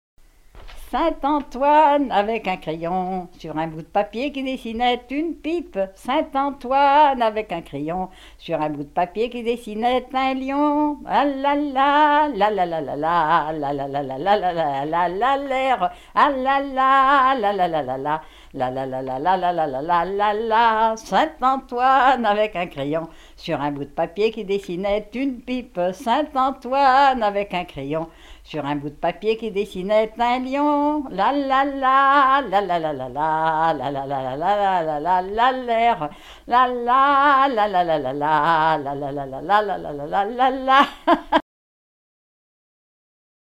Chants brefs - A danser
danse : scottich trois pas
Pièce musicale inédite